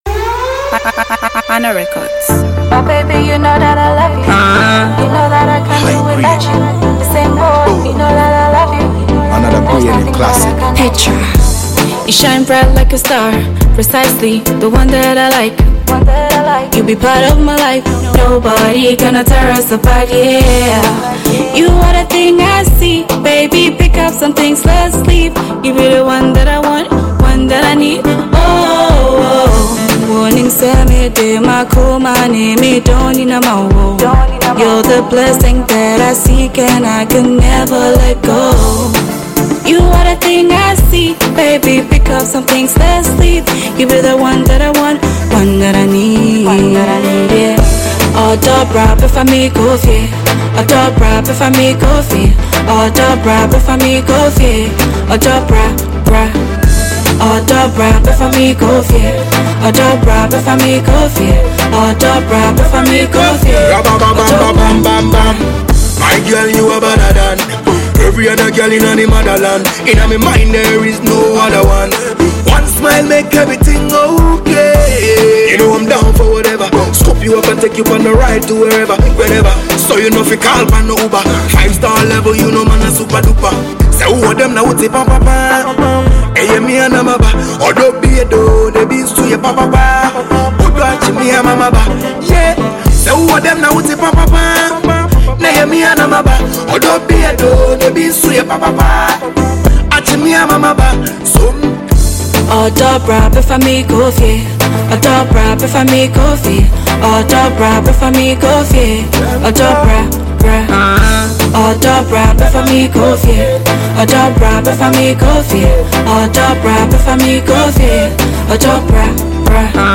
Ghanaian female singer